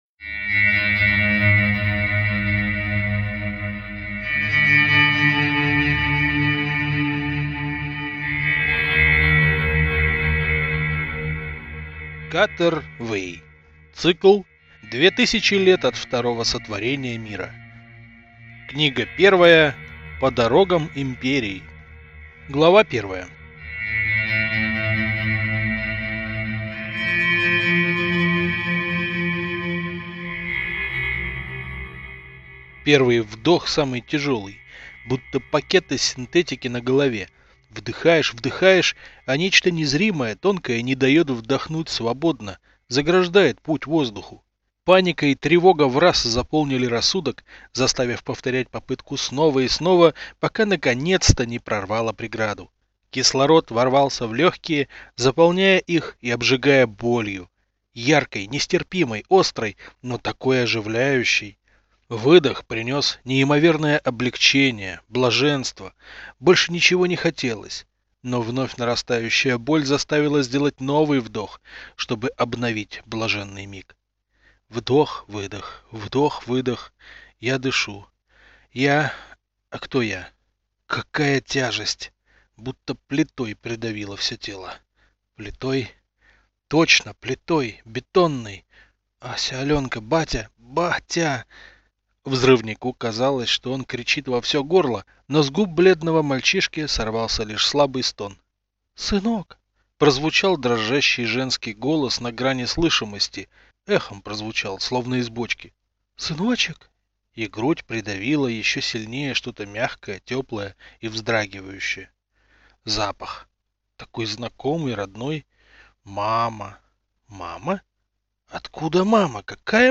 Аудиокнига По дорогам Империи | Библиотека аудиокниг